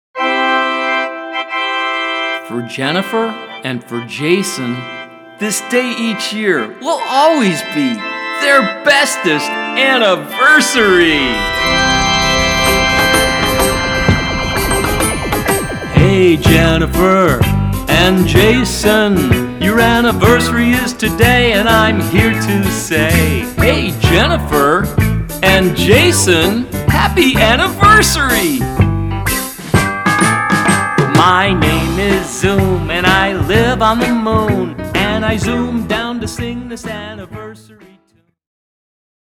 Sung to the delightful tune of our birthday song